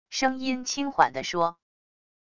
声音轻缓地说wav音频
声音轻缓地说wav音频生成系统WAV Audio Player